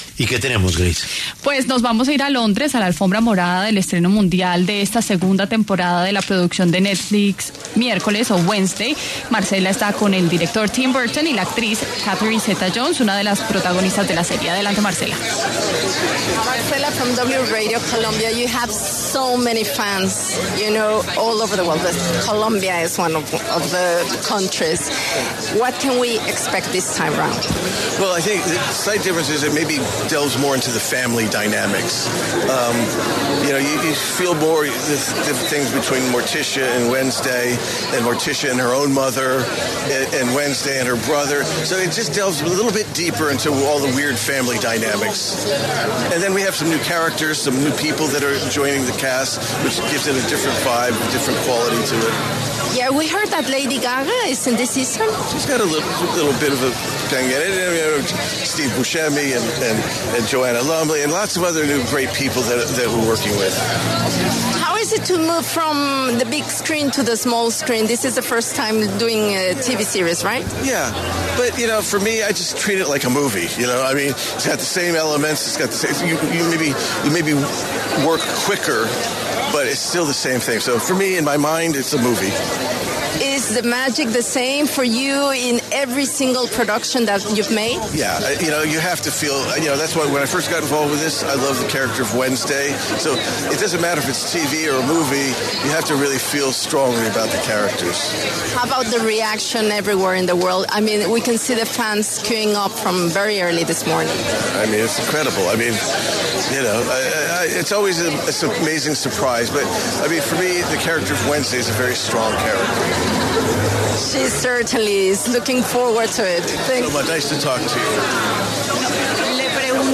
El director de cine y televisión, Tim Burton, conversó con La W, desde Londres, sobre la segunda temporada de la serie de Netflix ‘Merlina’.